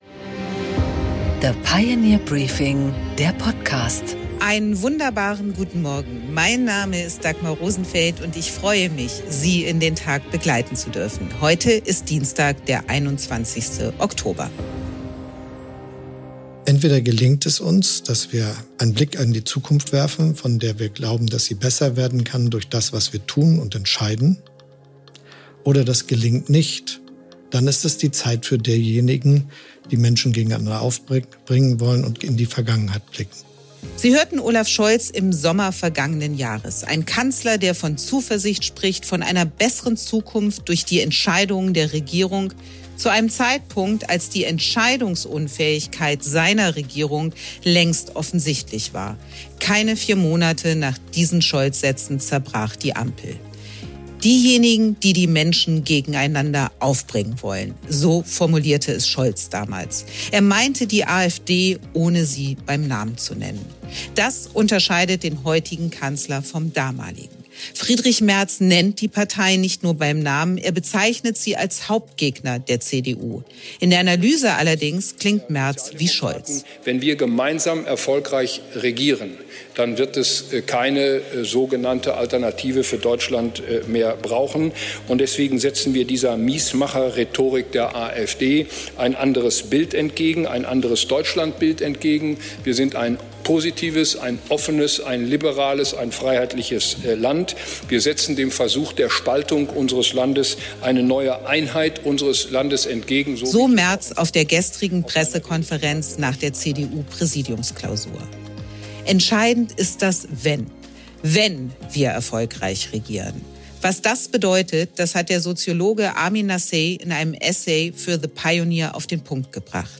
Interview mit Gordon Schnieder